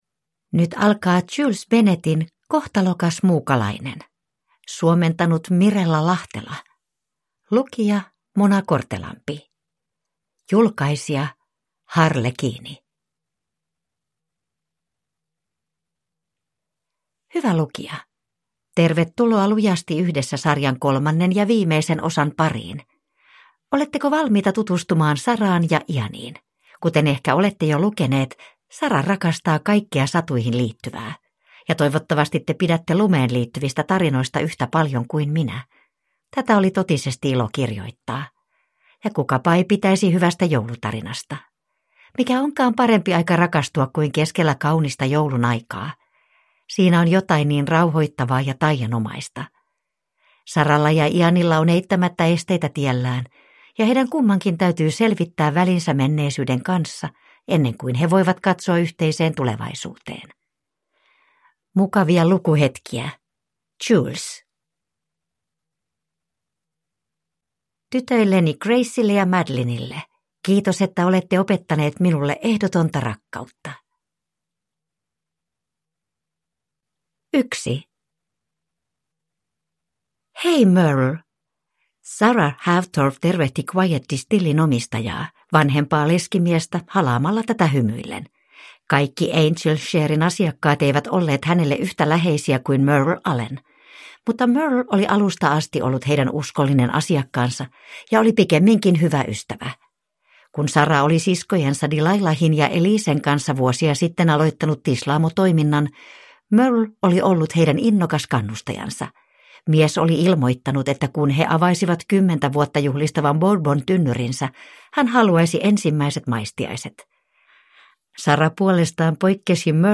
Kohtalokas muukalainen – Ljudbok – Laddas ner